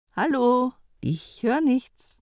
"He, Nudlaug, wos is?", ist die etwas forscher formulierte Version - beides sind Beispiele, wie der Computer in Zukunft auf eine nicht erfolgte Eingabe reagieren könnte.